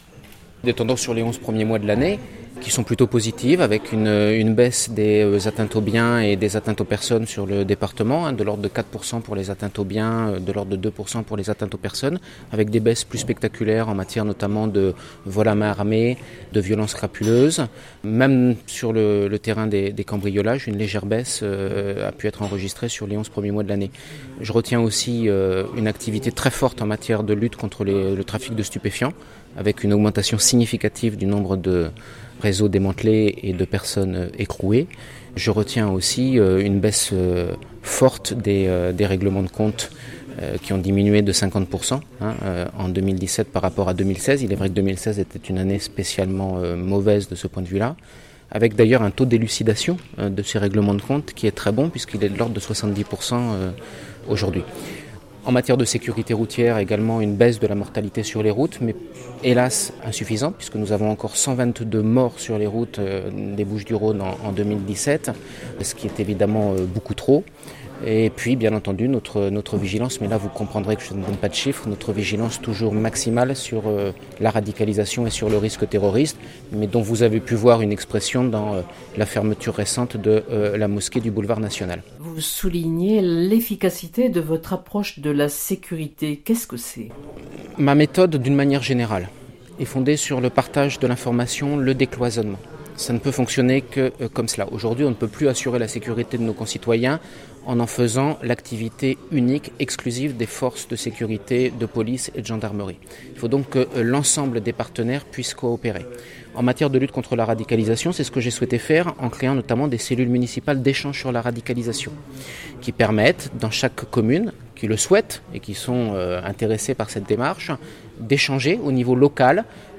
Lors des vœux 2018 à la presse, le nouveau préfet de police des Bouches-du-Rhône Olivier de Mazières a dressé un bilan de l’année écoulée avant de poursuivre avec les perspectives 2018.
Reportage